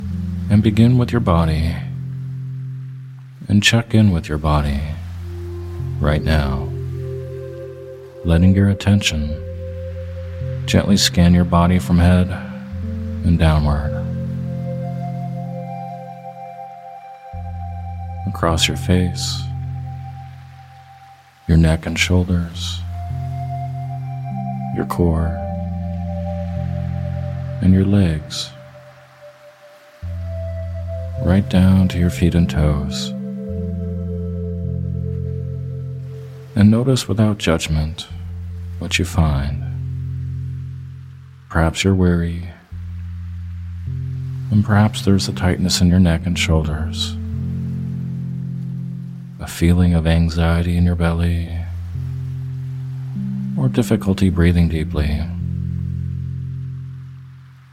Sleep Hypnosis For Overcoming Burnout – Hypnotic Labs
In this guided meditation or hypnosis audio, you’ll be guided in helping the symptoms of feeling burnt out.